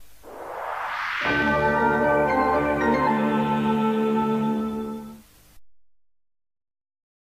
Un des nombreux jingles pub (200 ko)